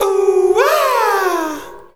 Track 14 - Vocal Oooh Aaah OS.wav